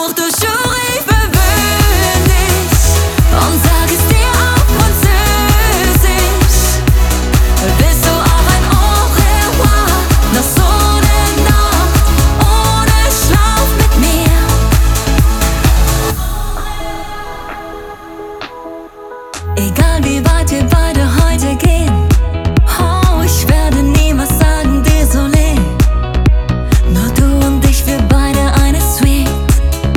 German Pop
Жанр: Поп музыка